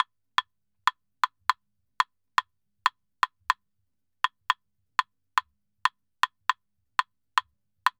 Claves_Salsa 120_3.wav